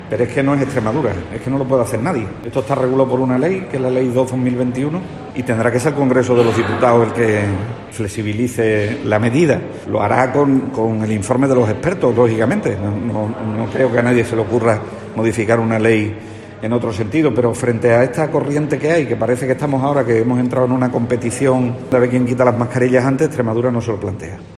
José María Vergeles ha realizado estas declaraciones a preguntas de los medios momentos antes de inaugurar este viernes en Mérida las jornadas de la Asociación Oncológica Extremeña (AOEX) 'No hablemos de enfermedad. Hablemos de salud y bienestar'.